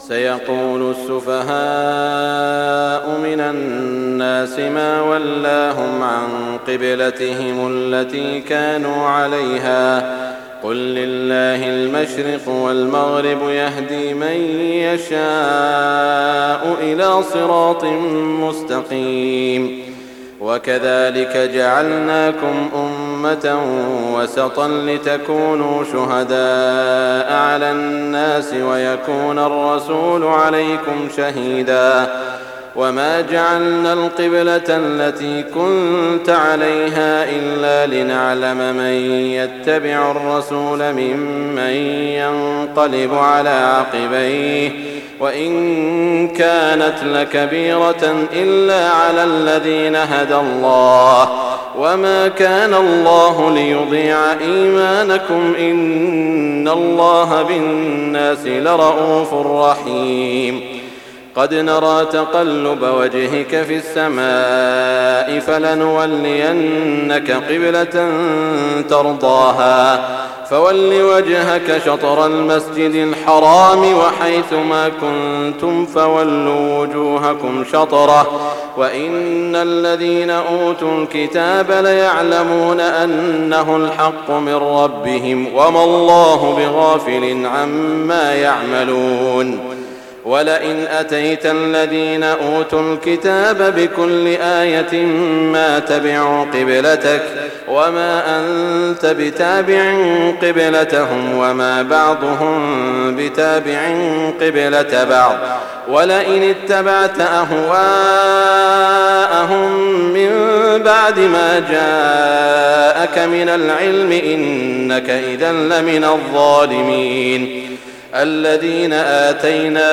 تهجد ليلة 22 رمضان 1419هـ من سورة البقرة (142-218) Tahajjud 22 st night Ramadan 1419H from Surah Al-Baqara > تراويح الحرم المكي عام 1419 🕋 > التراويح - تلاوات الحرمين